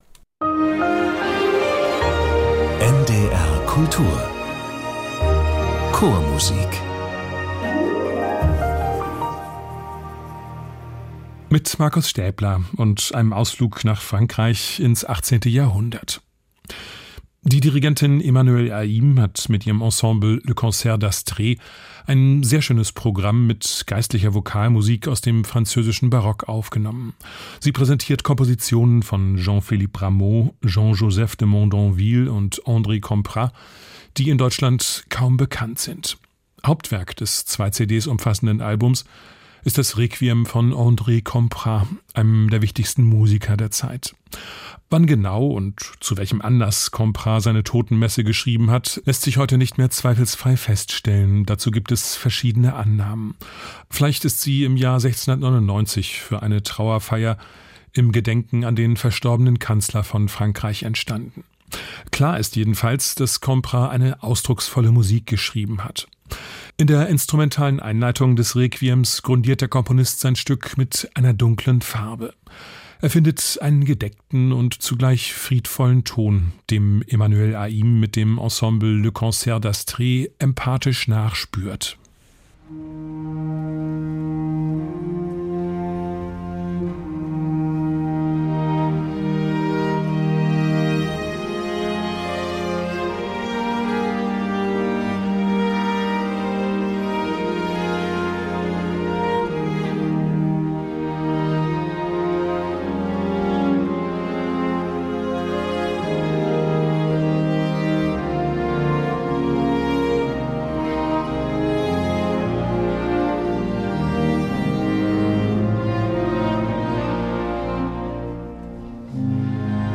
Geistliche Vokalmusik aus dem französischen Barock